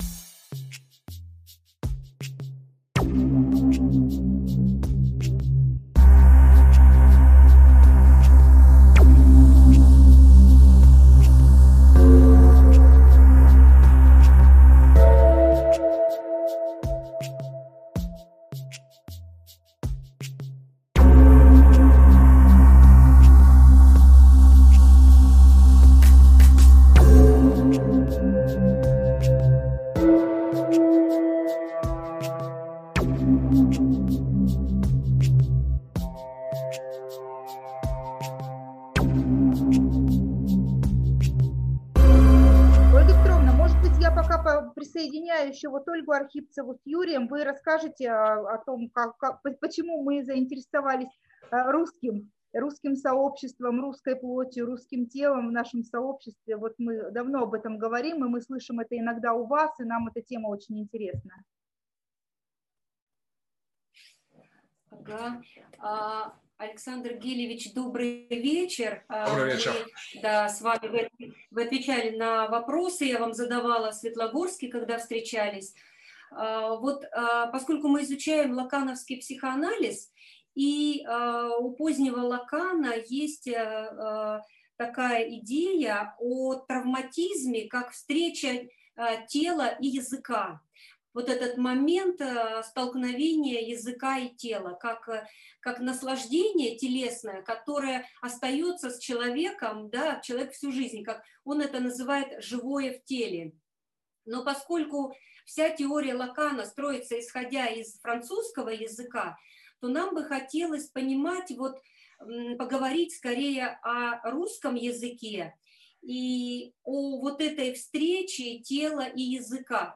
Философские беседы на различные темы А.Г.Дугина Вопросы и ответы Аргументы и методологии Философские стратагеммы Политика v политическая философия